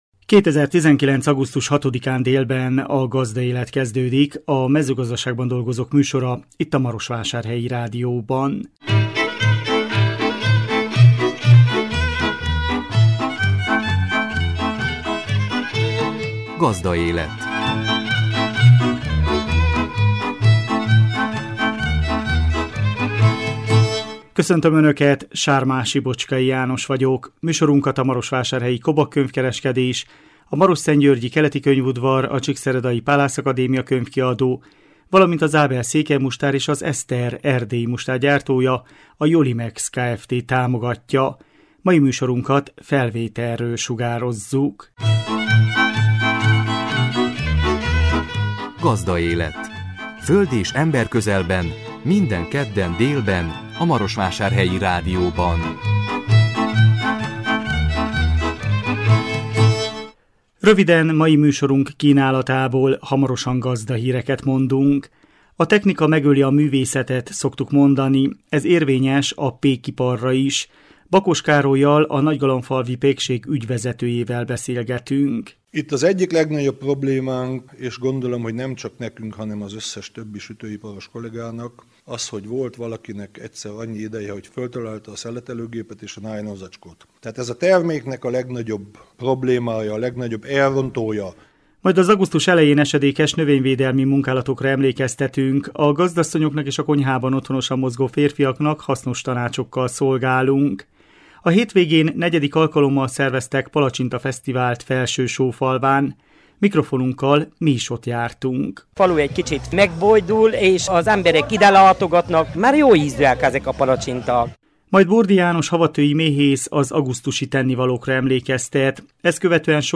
A hétvégén 4. alkalommal szerveztek palacsinta fesztivált Felsősófalván. Mikrofonunkkal mi is ott jártunk.